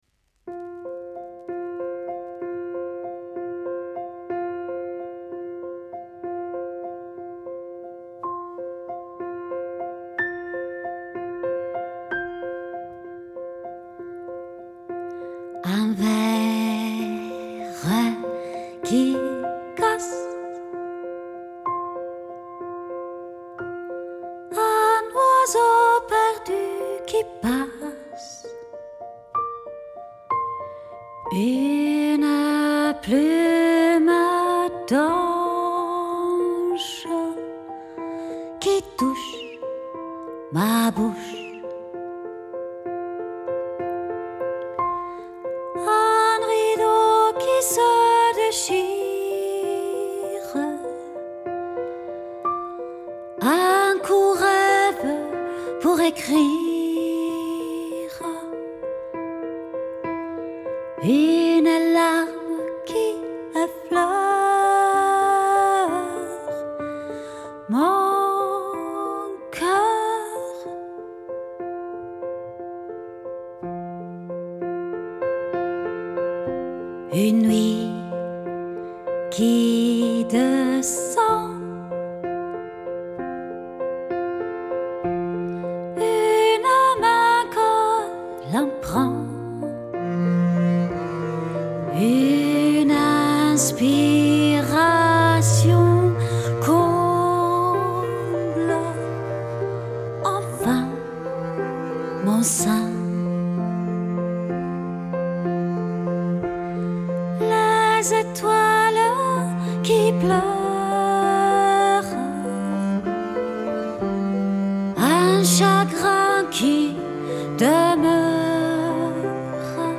Lyrics & Vocals
Piano
Double Bass
Cello